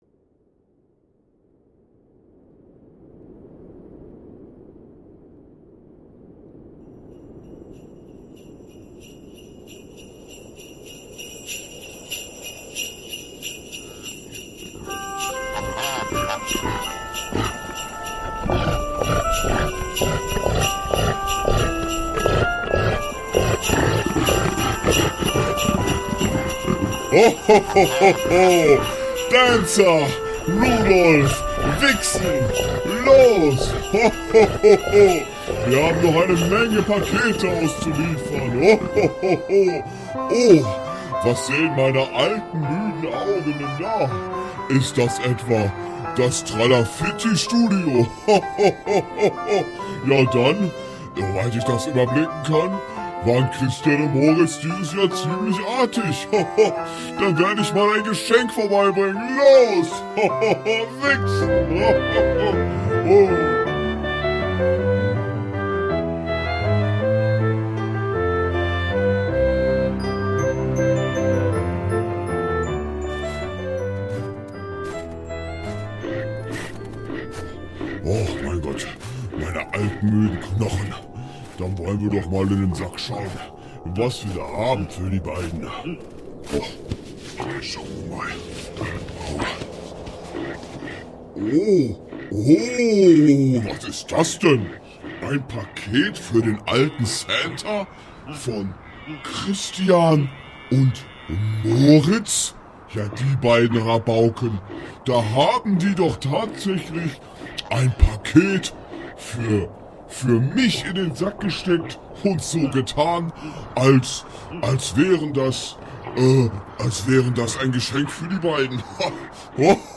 +++ DISCLAIMER +++ Liebe Hörerinnen, liebe Hörer und alle dazwischen und außerhalb, leider ist uns bei der Aufnahme dieser Ausgabe ein technischer Fehler unterlaufen, weshalb wir euch diesmal nicht die gewohnte Soundqualität bieten können. Unser Soundengineer hat in der Postproduktion alles gegeben, aber manchmal ist eben alles nur „stets bemüht“.